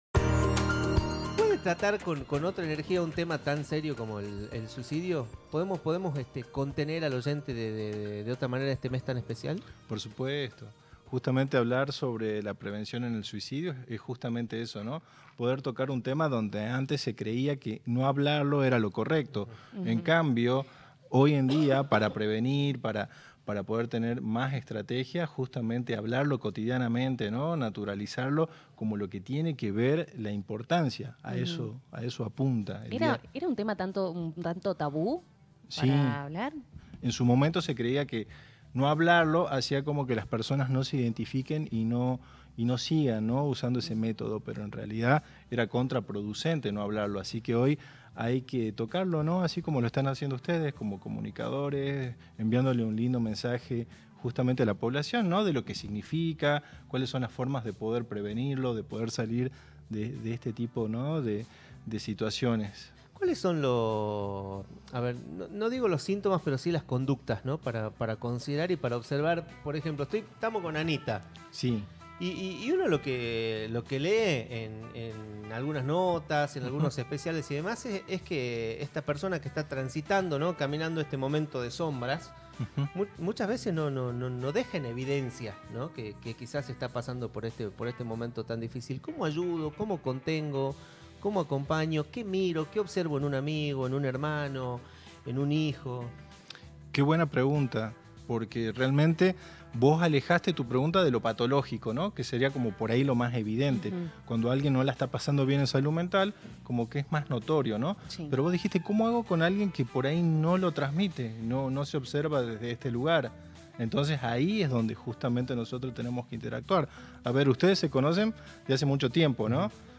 Psicólogo